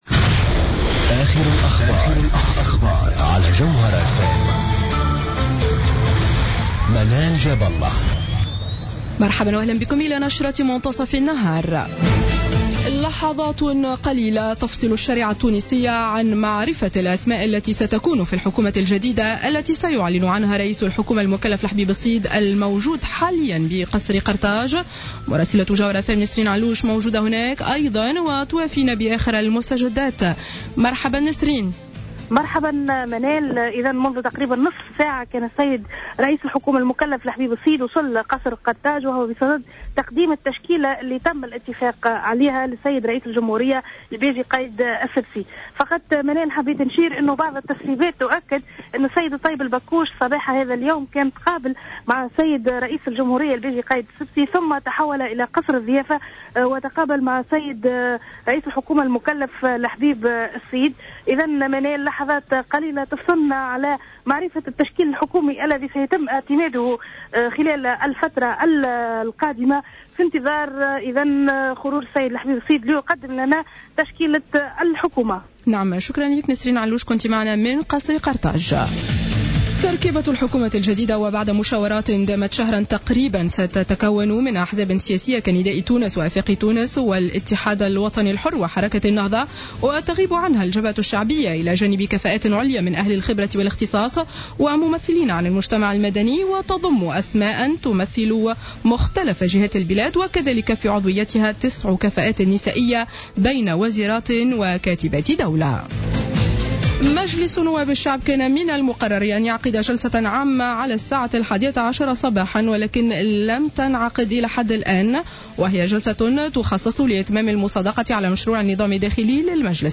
نشرة أخبار منصف النهار ليوم الاثنين 02-02-15